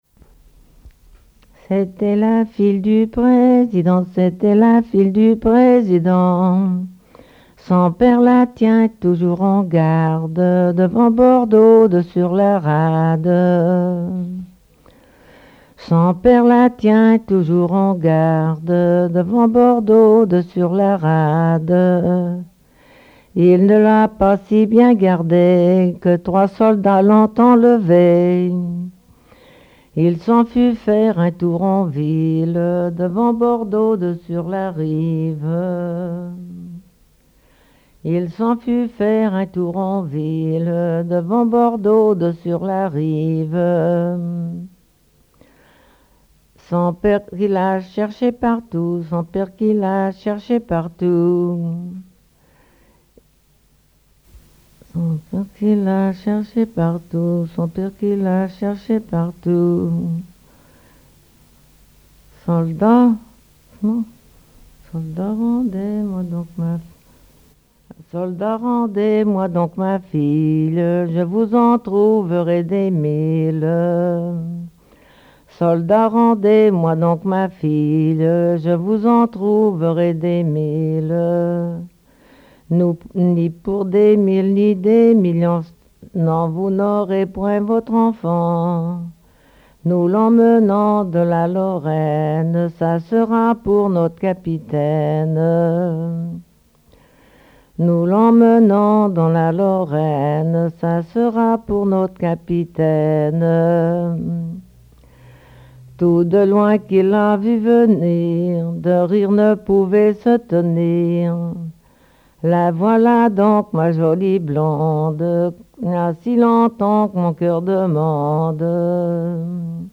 Genre strophique
collecte en Vendée
chansons traditionnelles